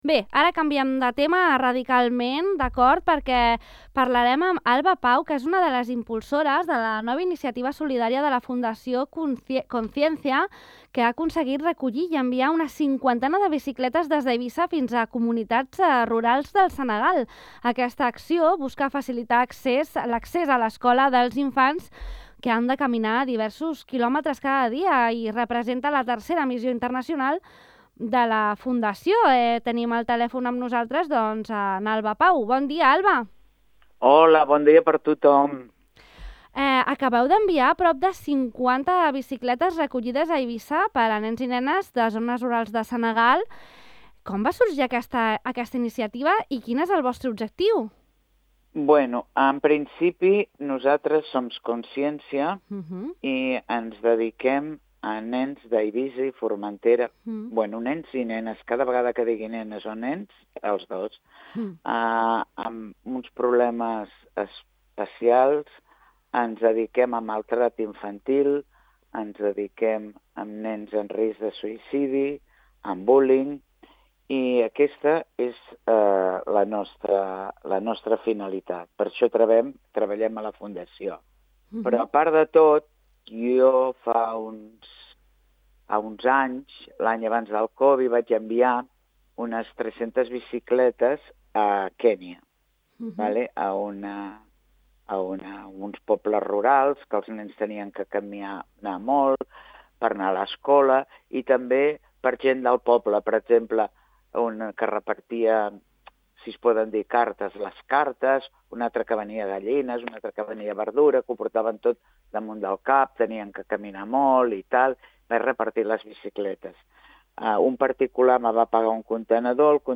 L’entrevistada d’avui ens ha relatat la dificultat logística que impliquen aquests enviaments, com en el cas del Marroc, on va prometre enviar-hi bicicletes, però no ho va aconseguir perquè les furgonetes llogades no podien sortir del país.